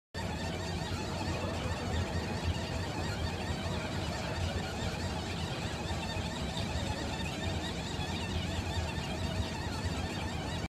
Звуки эскалатора в метро и в торговом центре, подъём и спуск, шум движущихся ступенек mp3 для монтажа видео.
4. Звуки скрипящего резинового поручня эскалатора